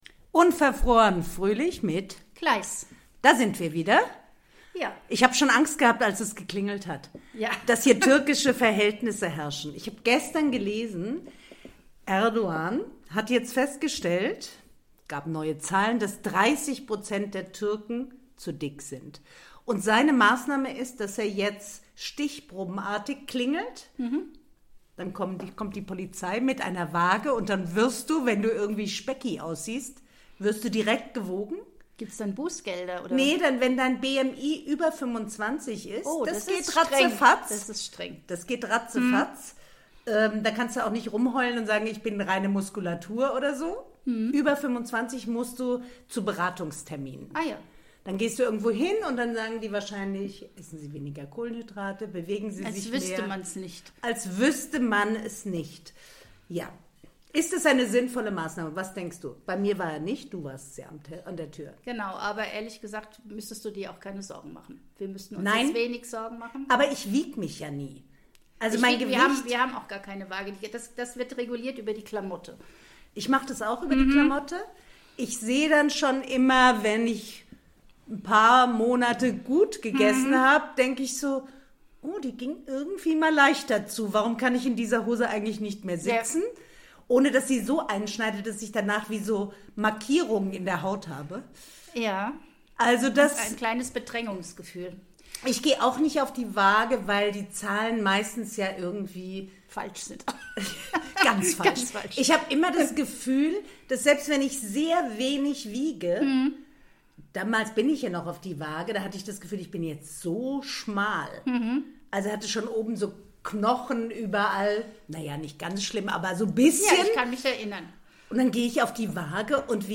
die beiden Podcasterinnen